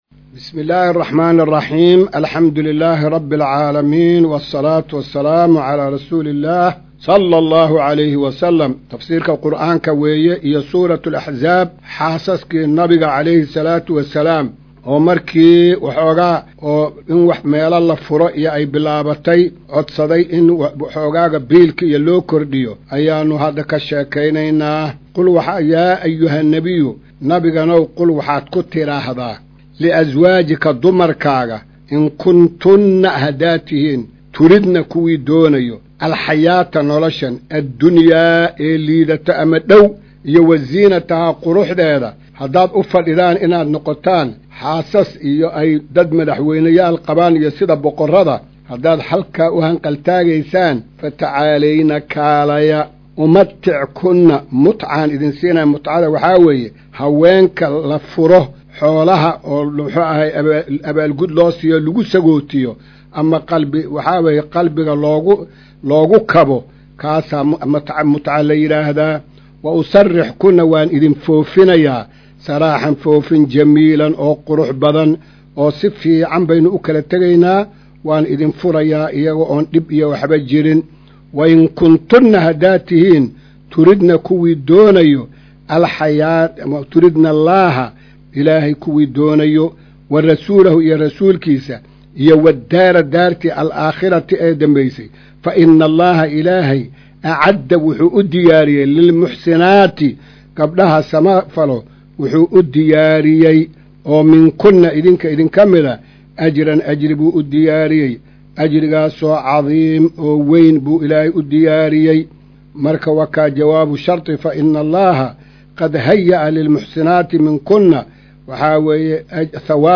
Maqal:- Casharka Tafsiirka Qur’aanka Idaacadda Himilo “Darsiga 199aad”